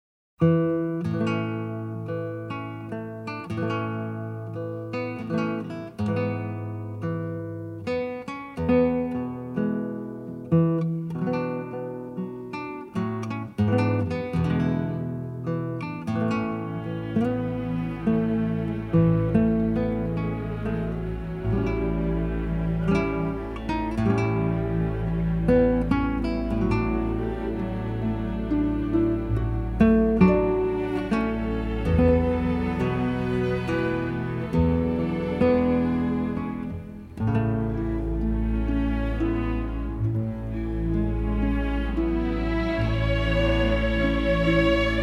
The score is a sensitive gem of melody and Americana
acoustic guitar
remixed and mastered from the original 1/2" stereo tapes.